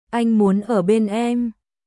Anh muốn ở bên emアイン ムオン オー ベン エム君と一緒にいたい